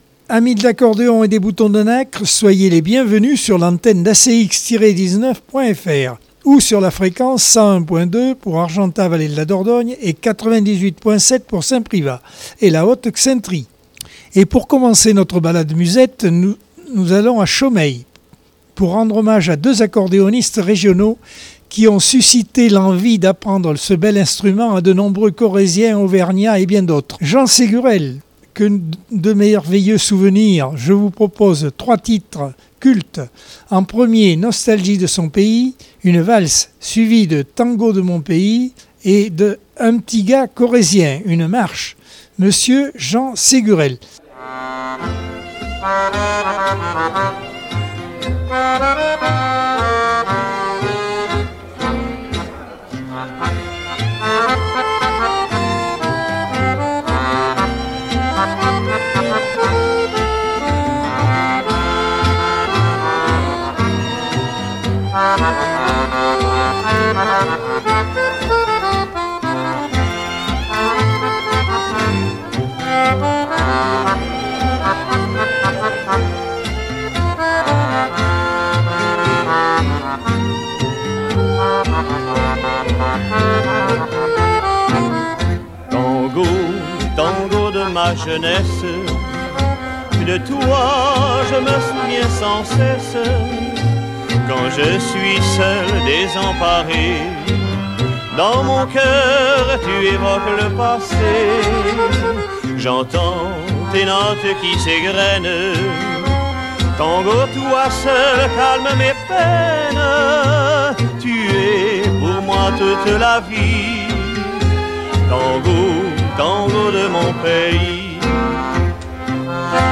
Accordeon 2022 sem 48 bloc 1.